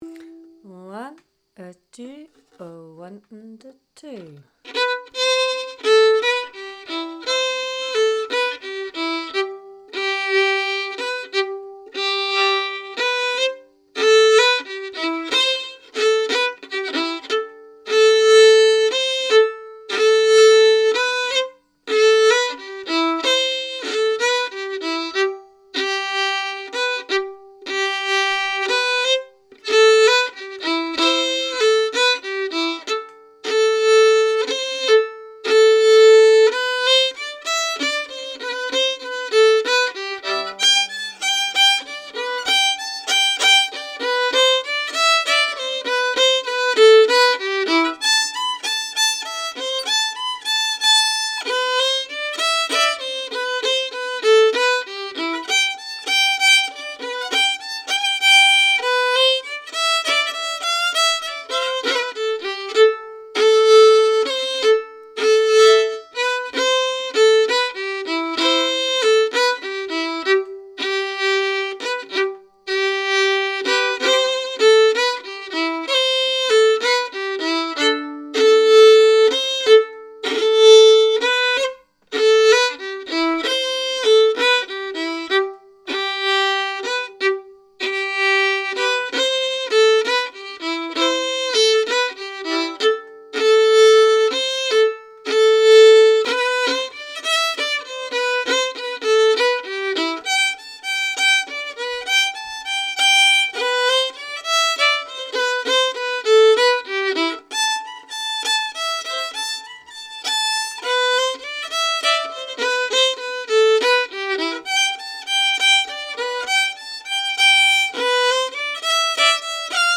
Key: C
Form: Jig
Played slowly for learning
Genre/Style: Morris Dance jig
PetticoateLooseSlow.mp3